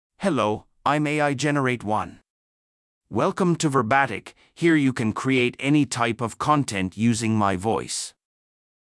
MaleEnglish (United States)
AIGenerate1 is a male AI voice for English (United States).
Voice sample
Listen to AIGenerate1's male English voice.
AIGenerate1 delivers clear pronunciation with authentic United States English intonation, making your content sound professionally produced.